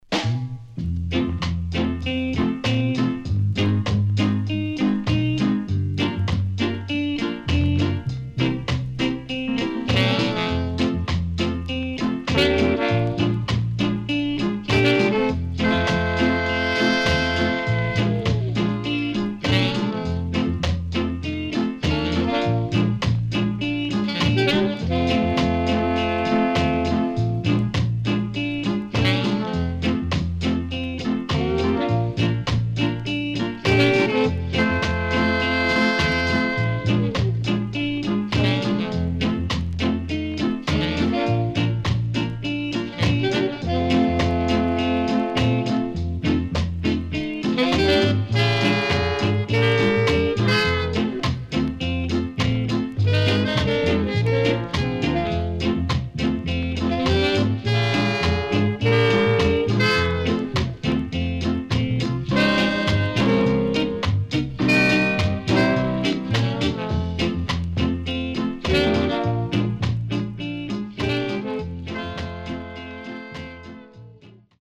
ROCKSTEADY
SIDE A:少しチリノイズ入りますが良好です。